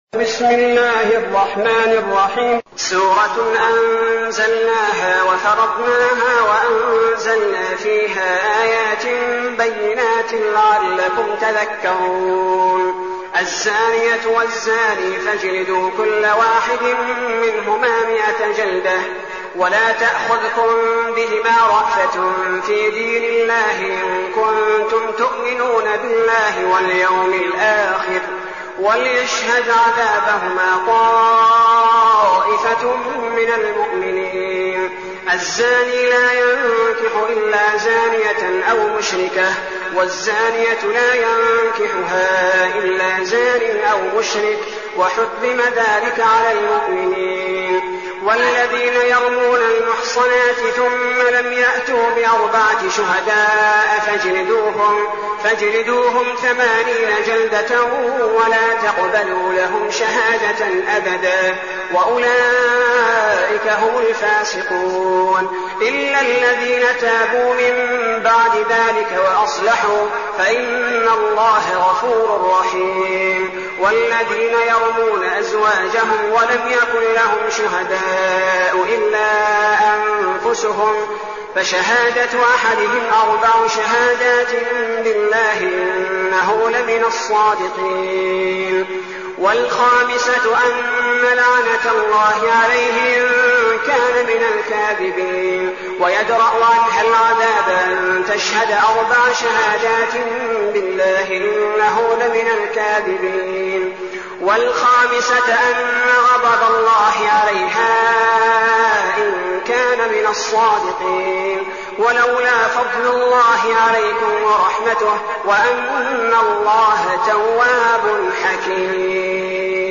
المكان: المسجد النبوي الشيخ: فضيلة الشيخ عبدالباري الثبيتي فضيلة الشيخ عبدالباري الثبيتي النور